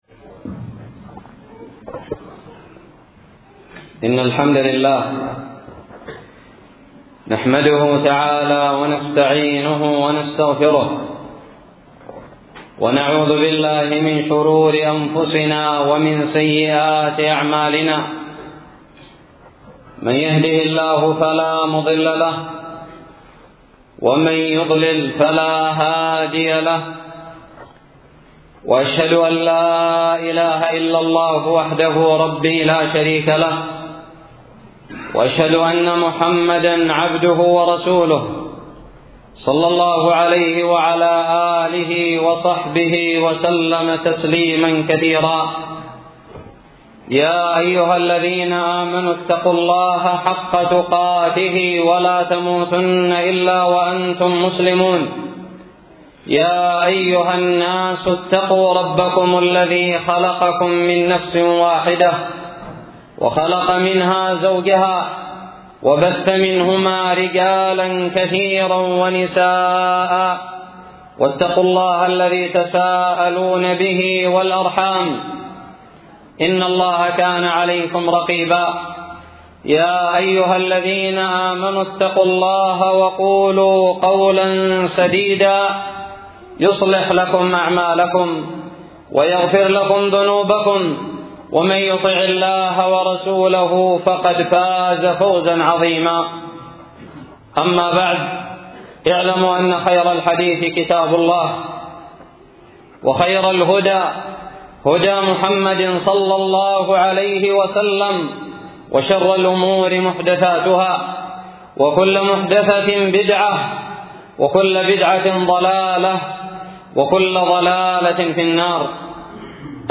خطب الجمعة
ألقيت بدار الحديث السلفية للعلوم الشرعية بالضالع في عام 1438هــ